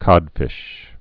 (kŏdfĭsh)